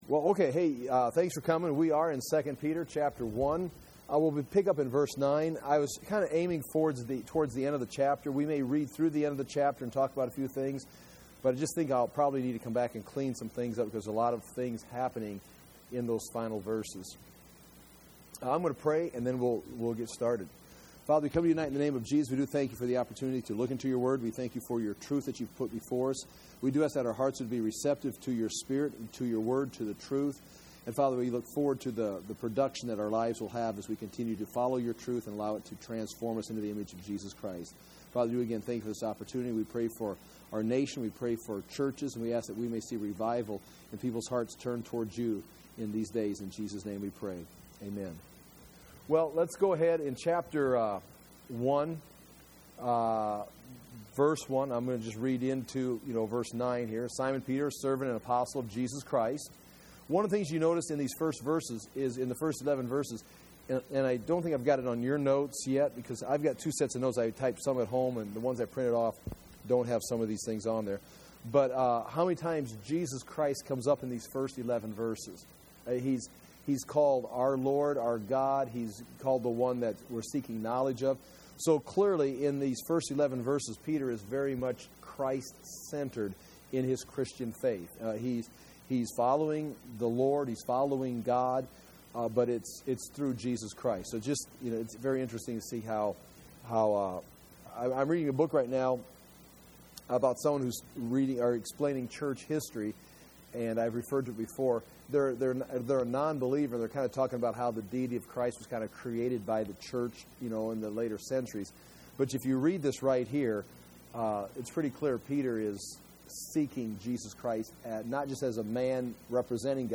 Second Peter verse by verse Bible teaching